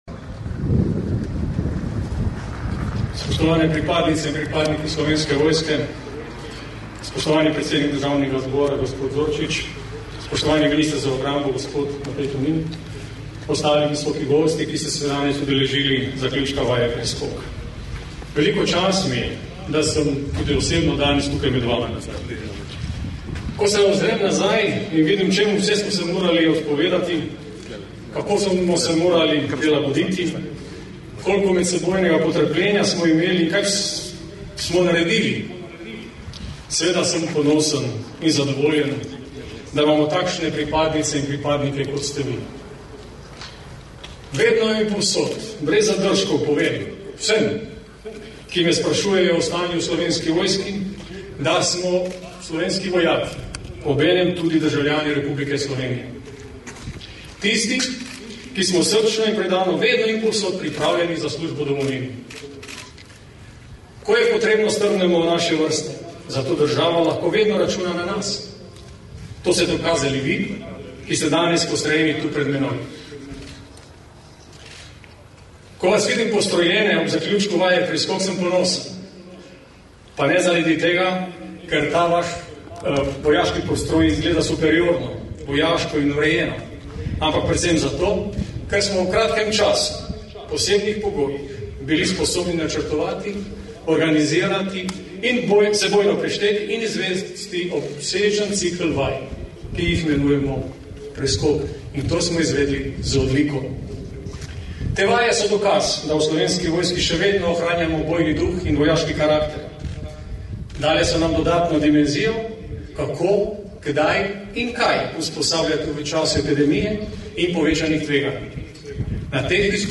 minister za obrambo mag. Matej Tonin - govor